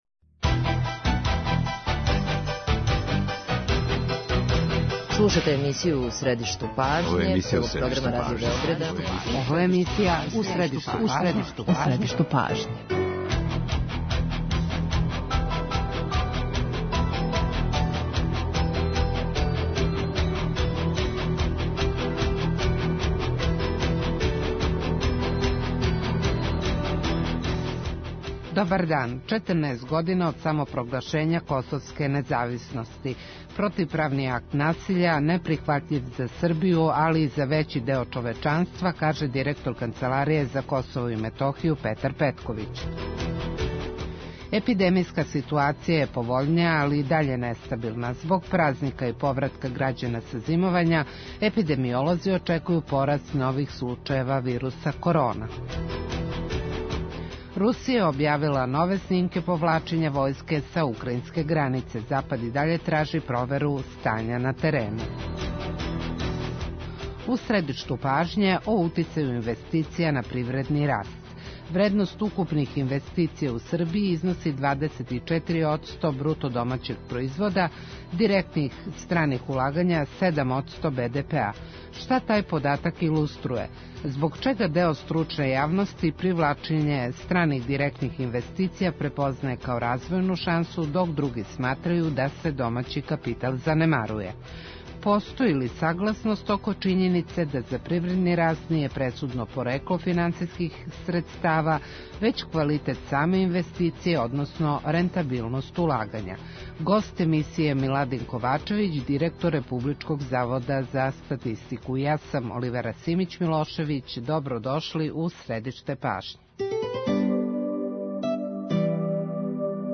Гост емисије је Миладин Ковачевић, директор Републичког завода за статистику, економиста.